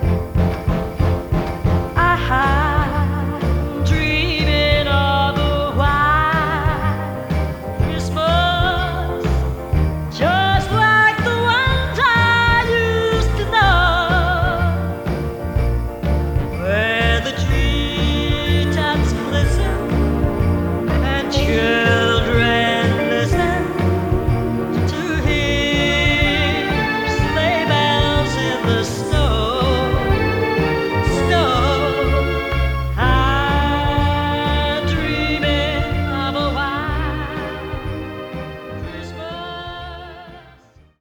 Spector version